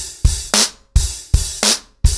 BlackMail-110BPM.3.wav